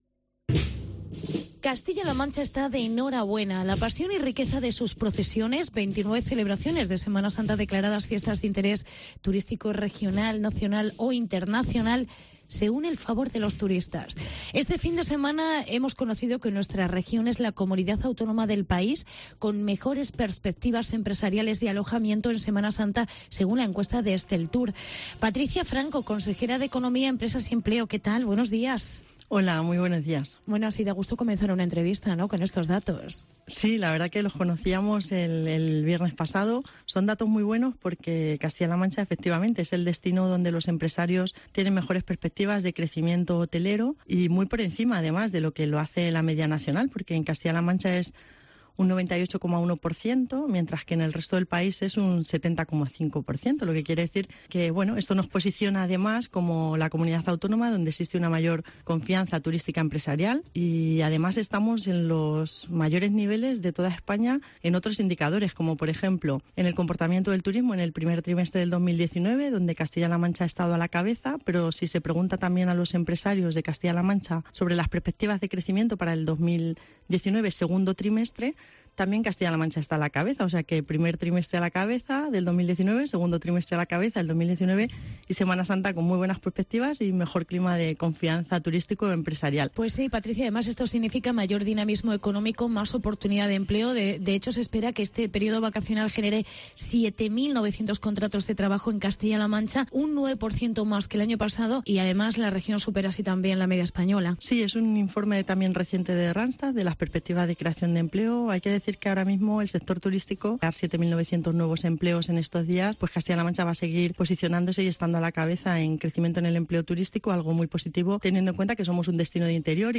AUDIO: Buenos datos turísticos para Semana Santa en CLM. Descubre las mejores procesiones. Entrevista Patricia Franco